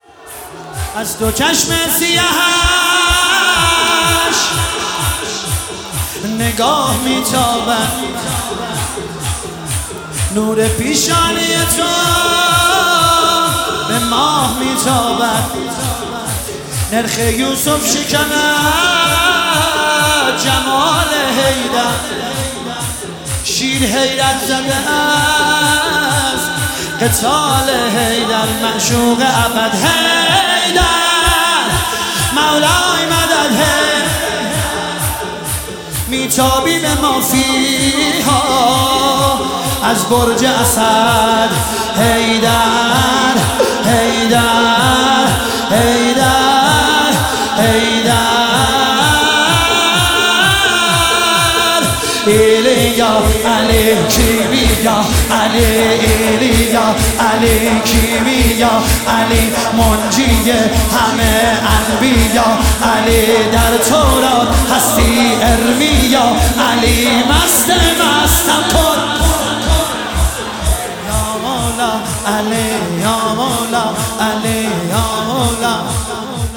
مداحی شور
شب 23 ماه رمضان 1446